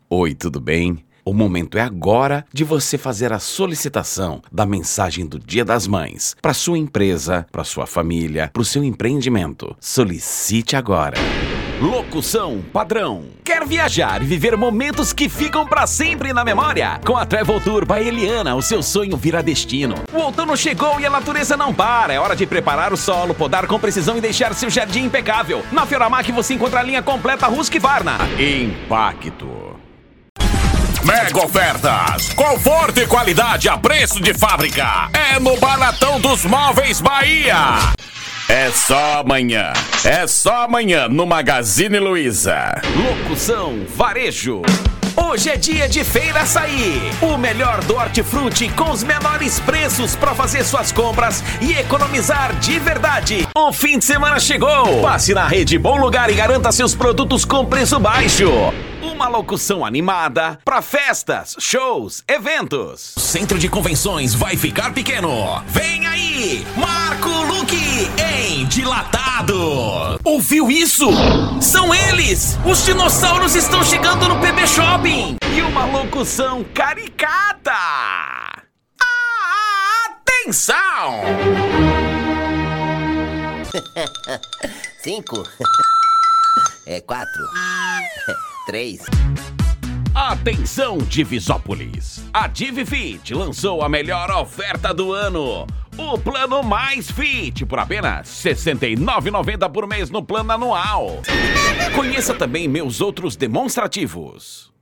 Spot Comercial
Vinhetas
Padrão
Impacto
Animada
Caricata